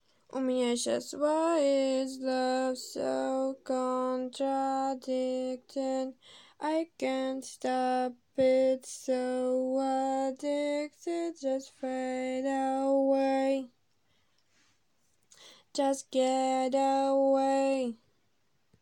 𝑉𝑜𝑖𝑐𝑒 𝑠𝑝𝑜𝑖𝑙𝑒𝑟
Сорри за голос🙏🏼 или он нормальный?